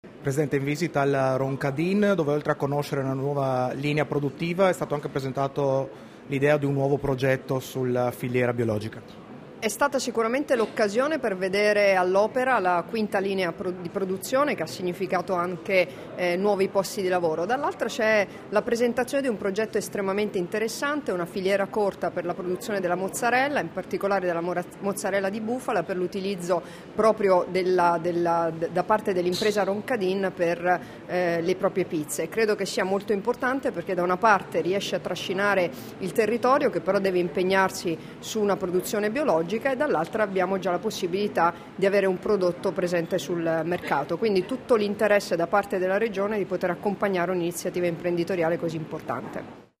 Dichiarazioni di Debora Serracchiani (Formato MP3) [797KB]
a margine della visita allo stabilimento della Roncadin S.p.A., rilasciate a Meduno il 30 novembre 2015